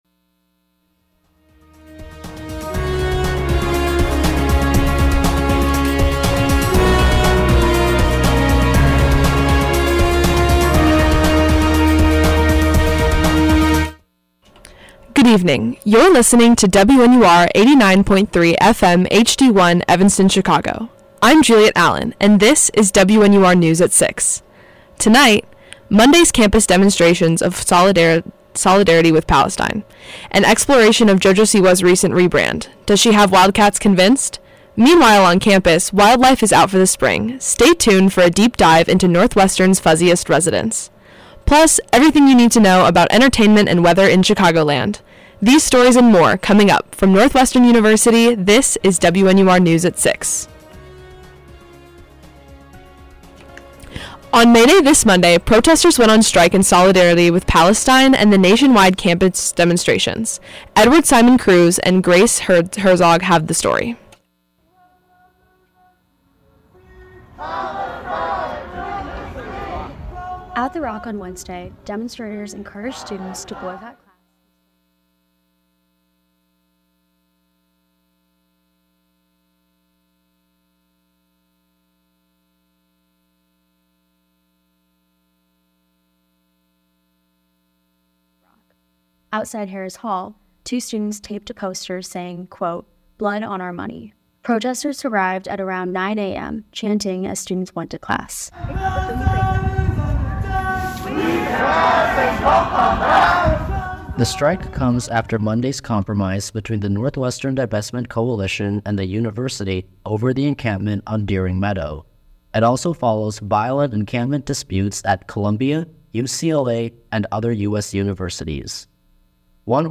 May 3, 2024: May Day Protest, JoJo Siwa, Warmer weather, Concert Countdown, Fair Weather Friends. WNUR News broadcasts live at 6 pm CST on Mondays, Wednesdays, and Fridays on WNUR 89.3 FM.